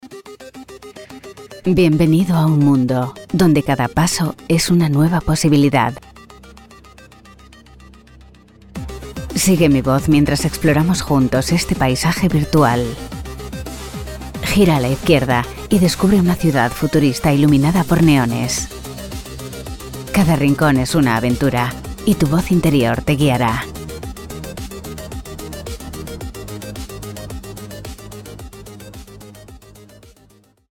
Versatile, Elegant, Sincere, Warm tones. 30-40.
Informative, Confident